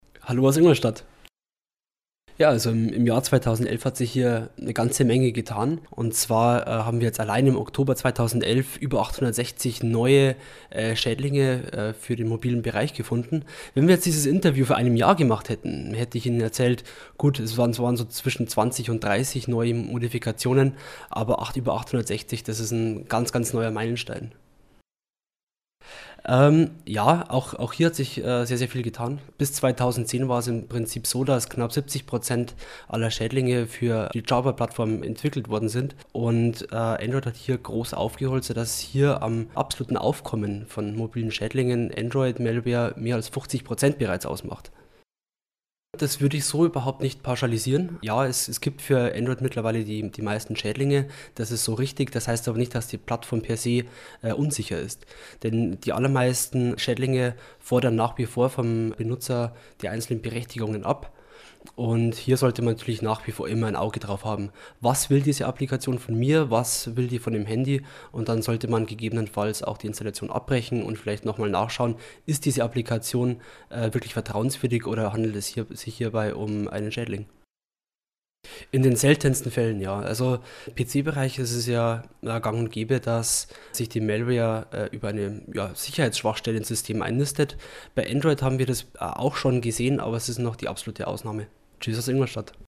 Kollegengespräch: Mobiles Surfen wird gefährlicher
O-Töne / Radiobeiträge, , ,